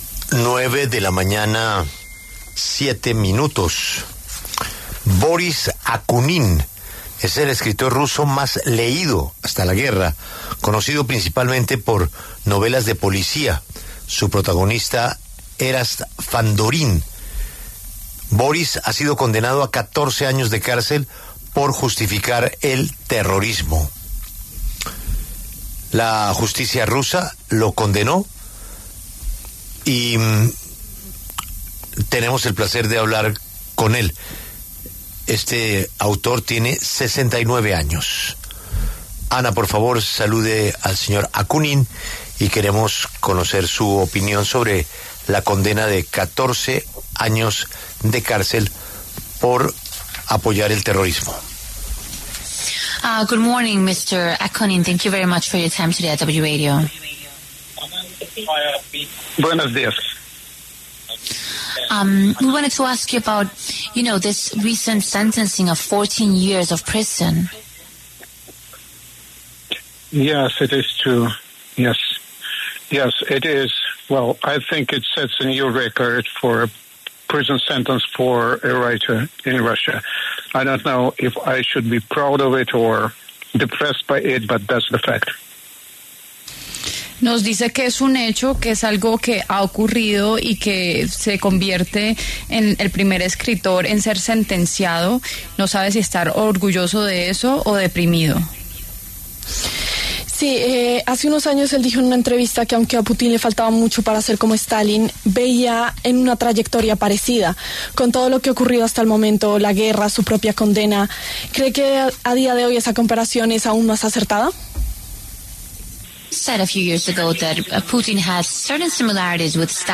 El escritor ruso Boris Akunin pasó por los micrófonos de La W, con Julio Sánchez Cristo, para hablar sobre su reciente condena a 14 de cárcel en Rusia tras ser acusado de “justificar el terrorismo”, entre otros cargos.